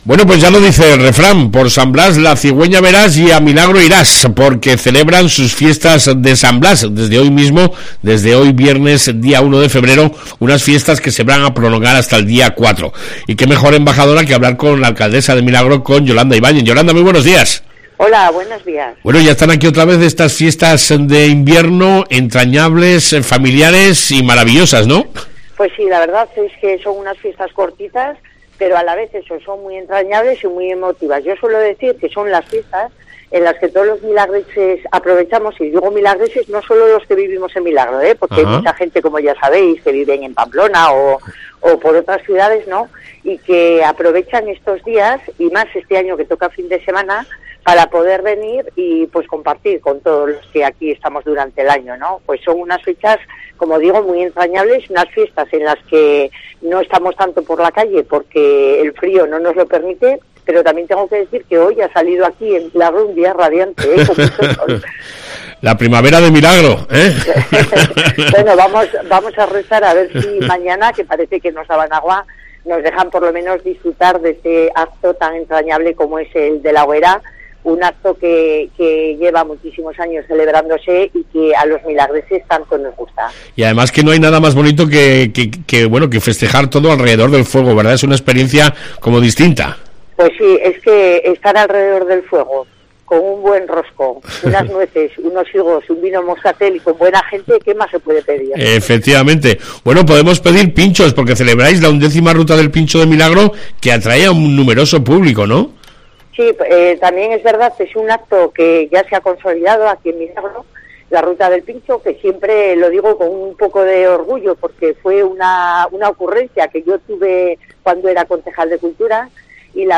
AUDIO: HABLAMOS CON YOLANDA IBAÑEZ , AVCALDESA